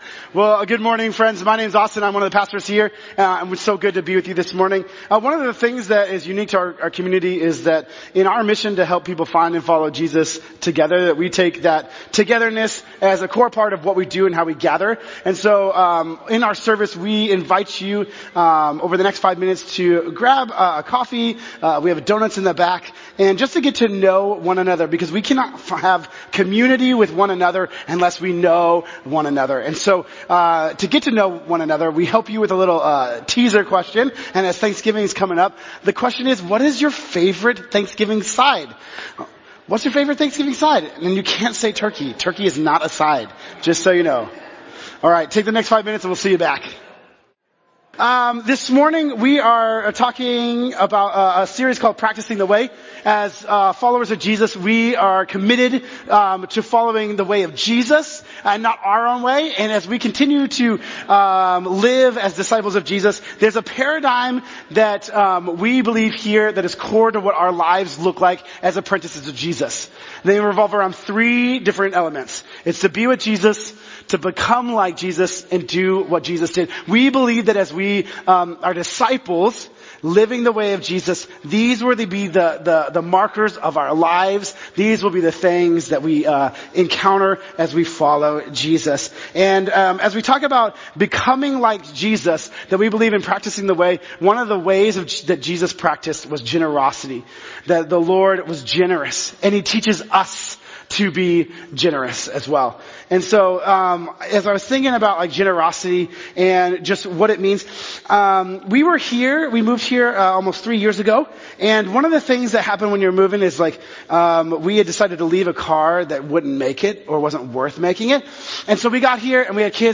Join us here for our service at 10AM on Sunday, 11/17.